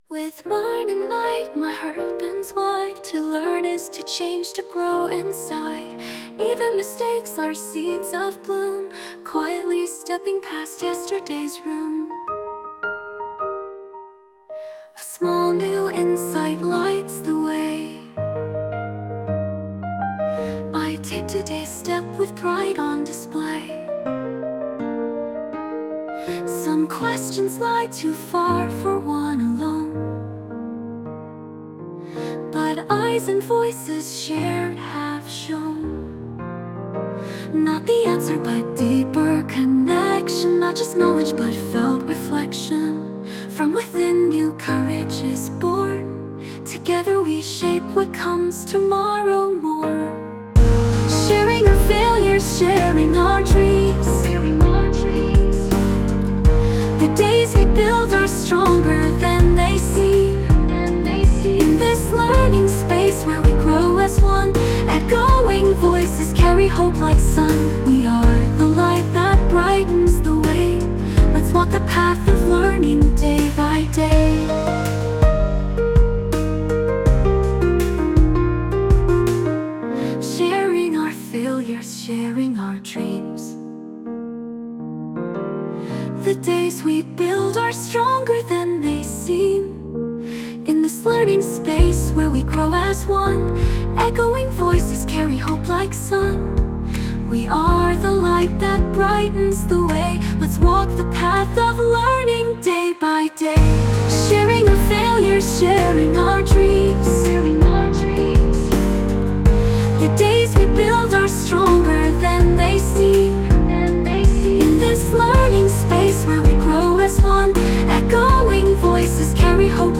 kyoiku-junior-high-school-song 2.mp3